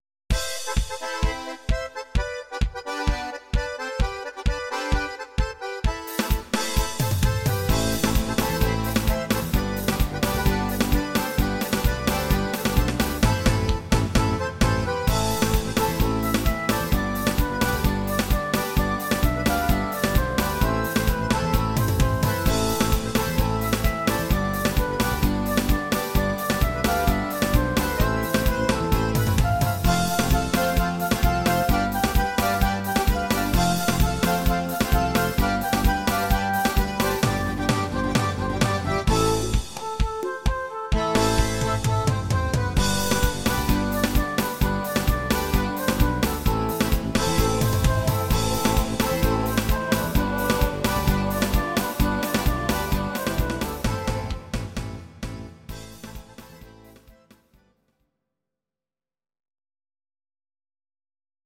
Audio Recordings based on Midi-files
Pop, Ital/French/Span, Medleys, 2010s